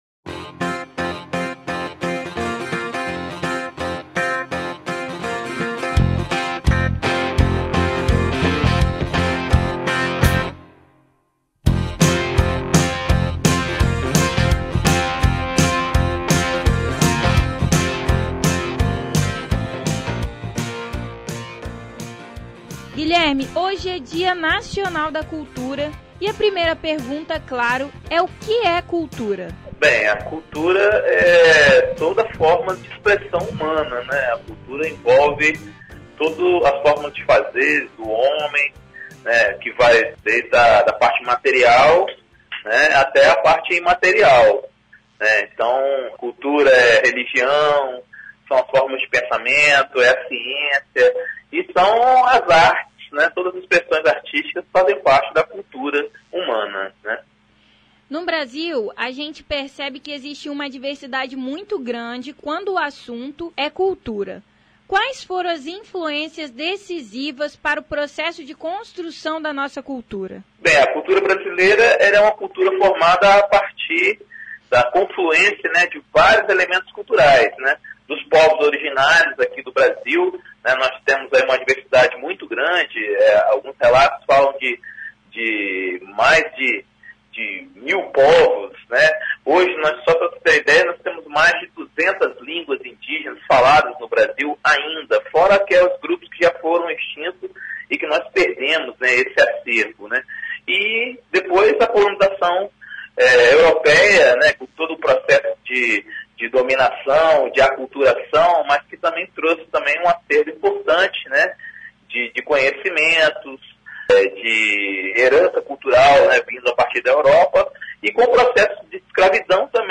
dia_nacional_da_cultura-_entrevista.mp3